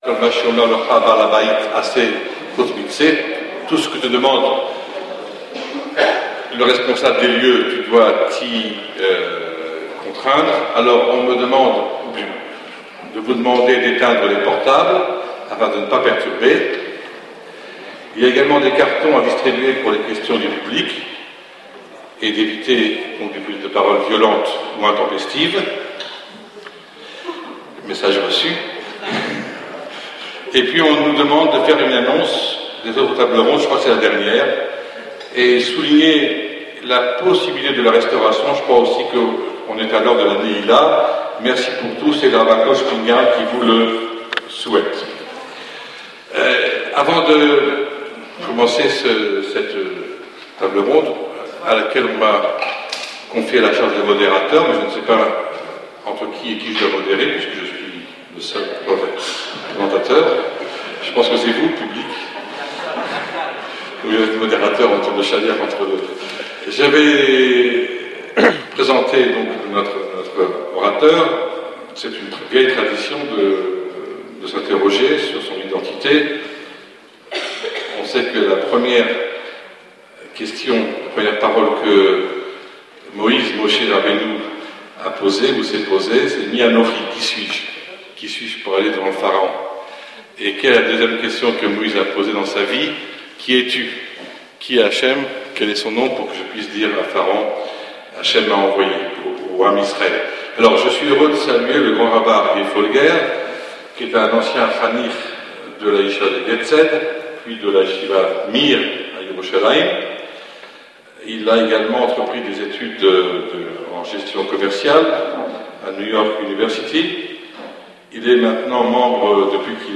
Kosher Day 2013 Strasbourg - le fichier audio Février dernier, j'étais l'un des conférenciers invités à la Journée du cacher / Kosher Day 2013 à Strasbourg. Le sujet de mon discours était: Pourquoi les différentes listes de cacheroute diffèrent-elle les unes des autres.